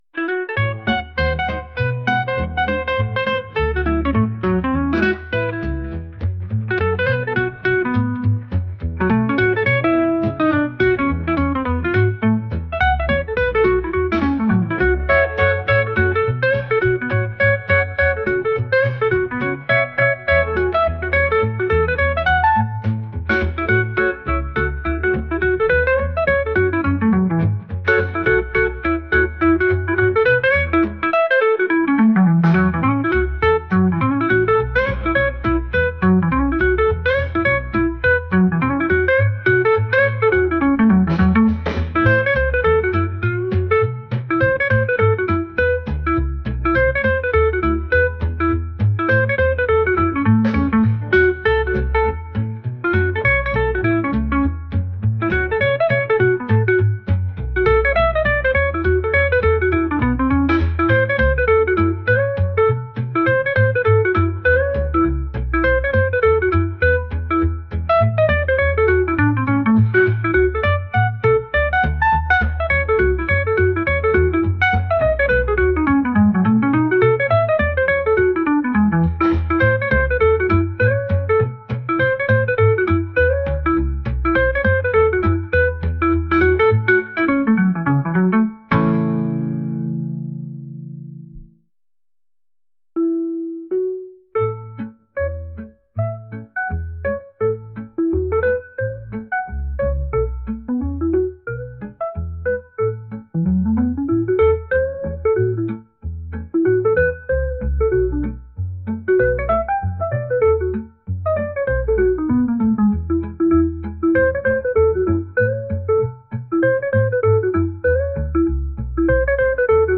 エレキギターのポップでサンバ風の曲です。